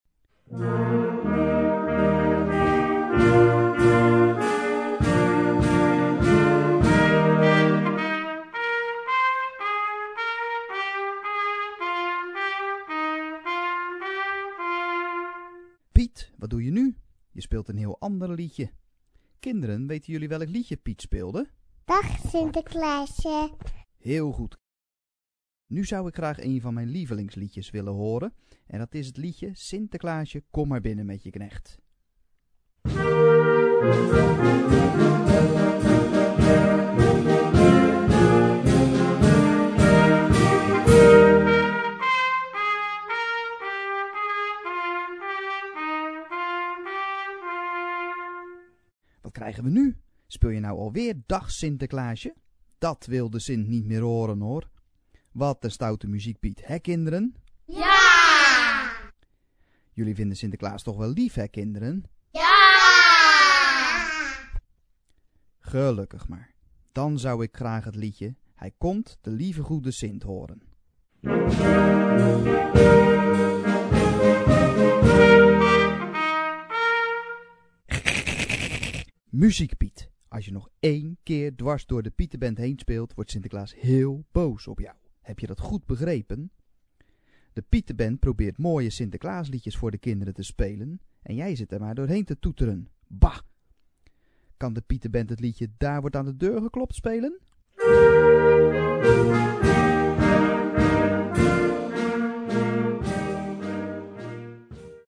brass band
Partitions pour ensemble flexible, 4-voix + percussion.